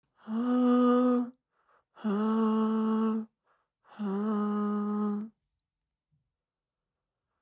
fl-studio-vocals-korrektur.mp3